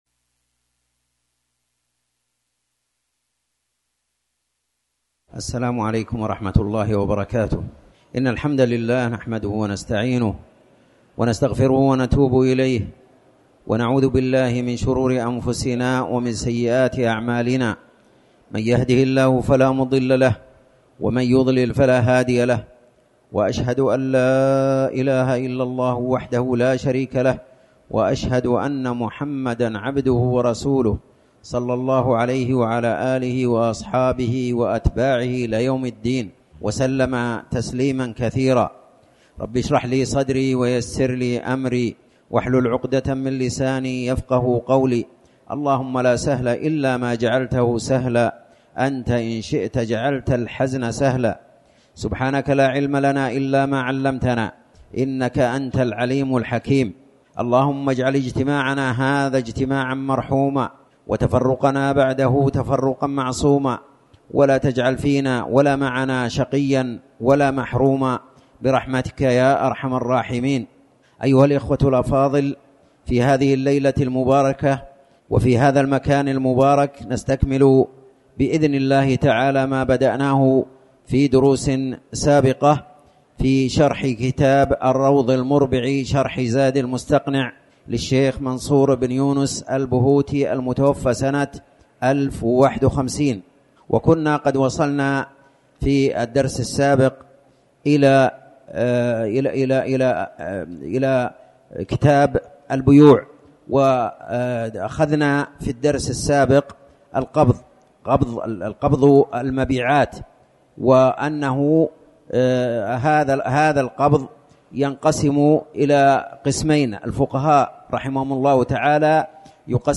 تاريخ النشر ٥ ربيع الأول ١٤٤٠ هـ المكان: المسجد الحرام الشيخ